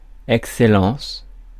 Ääntäminen
Synonyymit grâce Ääntäminen France Tuntematon aksentti: IPA: /ɛk.se.lɑ̃s/ Haettu sana löytyi näillä lähdekielillä: ranska Käännöksiä ei löytynyt valitulle kohdekielelle.